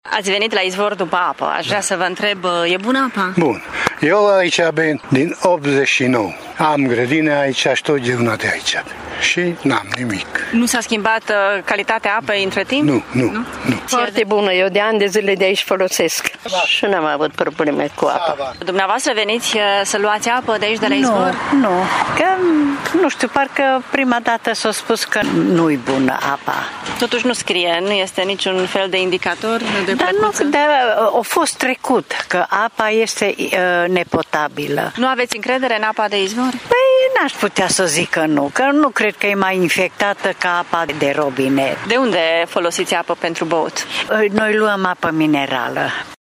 Oamenii au mai multă încredere în apa de izvor decât în cea de la … robinet: